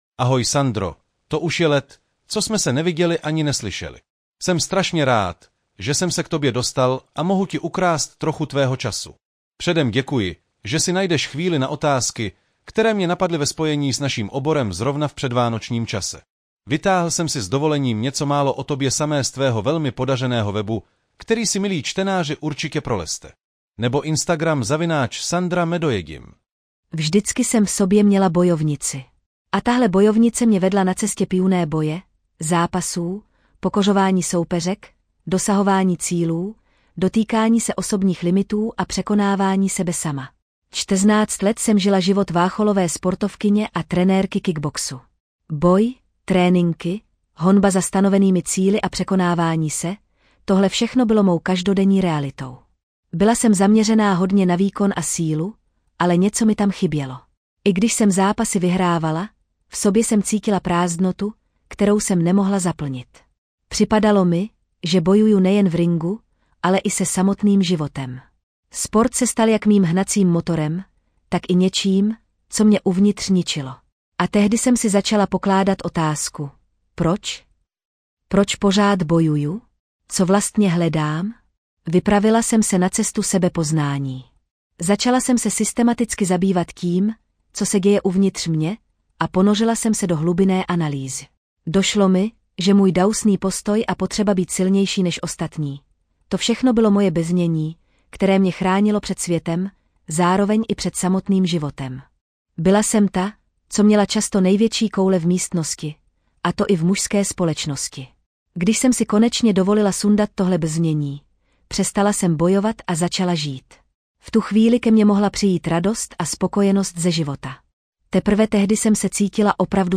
Namluvila ji umělá inteligence (AI), takže jí promiňte malé nedostatky. 🙂